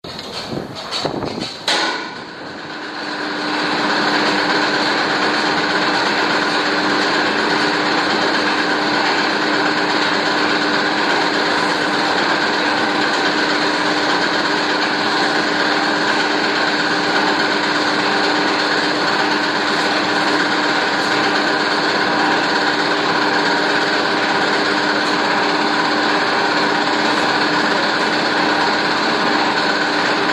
深山ダムの音
データ（MP3：473KB） フロート吊り上げ装置の音